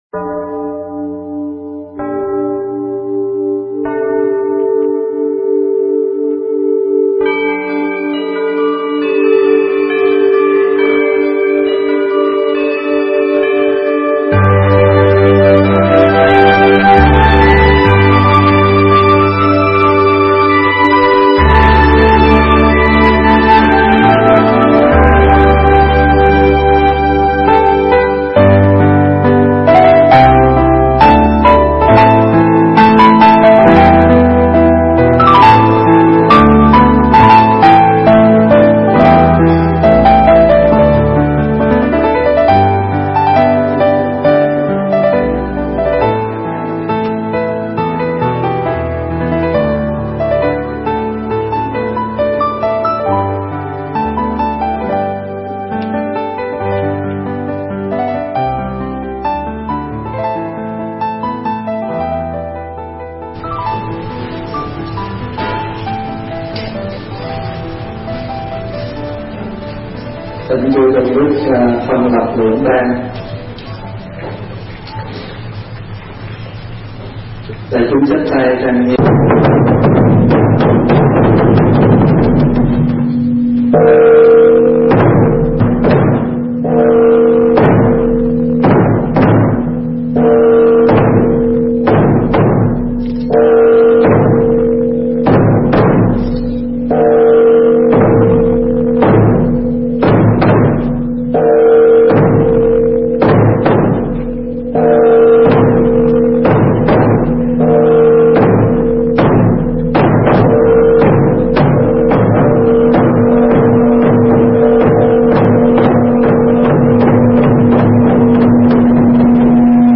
Mp3 thuyết pháp Lễ Hiệp Kỵ